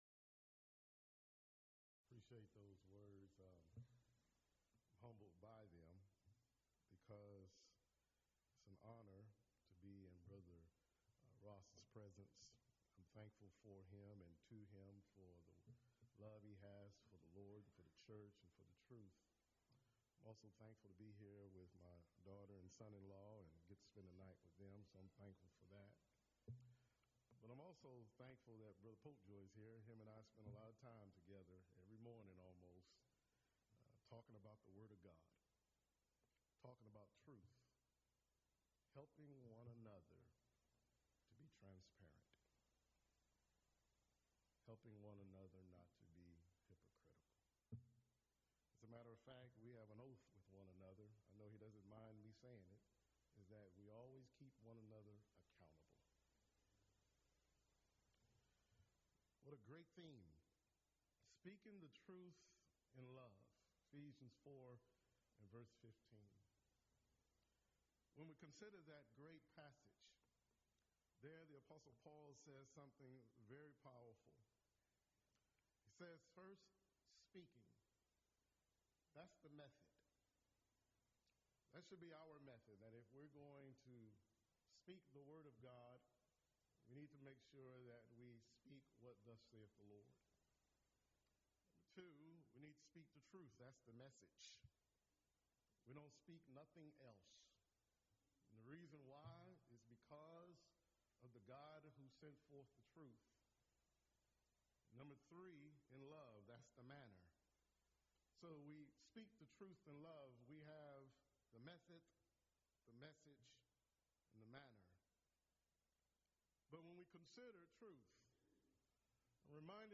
Event: 2nd Annual Colleyville Lectures